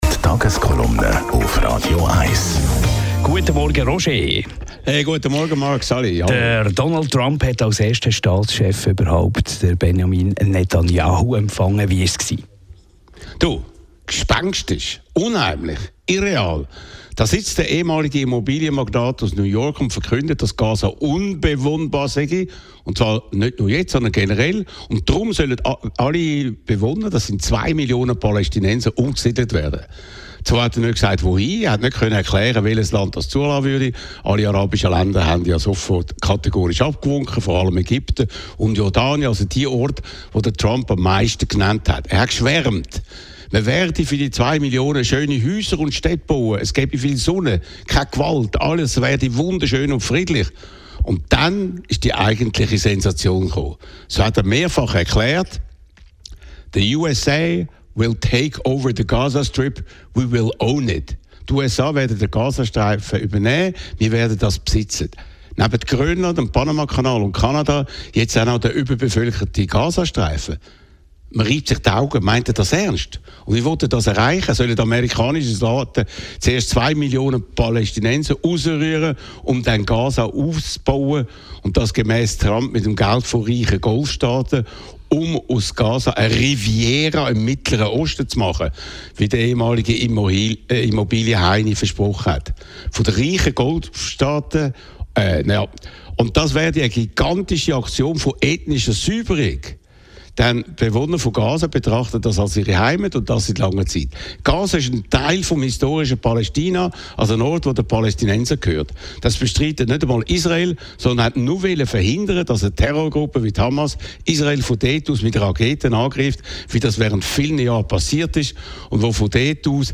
Roger Schawinski analysiert das Thema der Stunde.